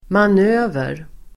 Uttal: [man'ö:ver]